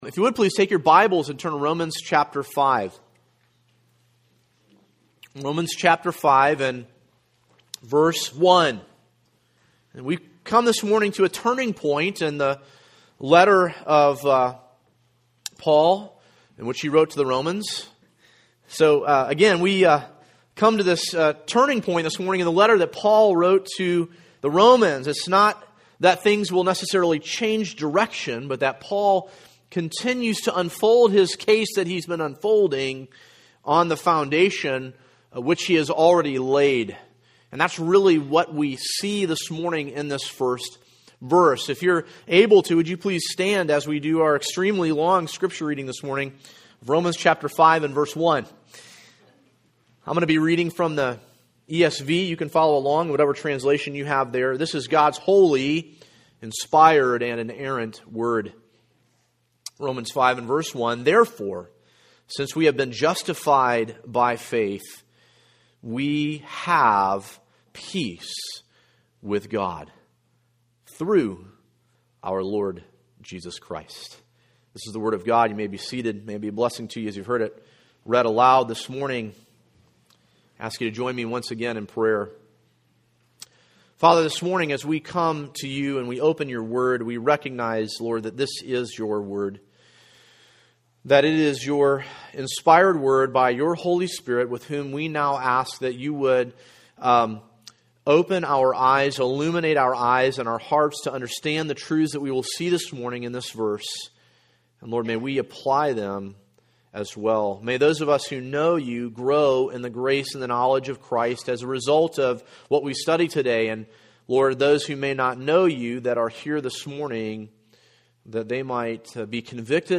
An exposition